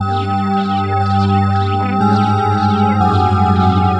描述：这个合成器循环是以恐怖的声音为基础的。使用Am合成器和Augur制作。
Tag: 电子乐 恐怖 合成器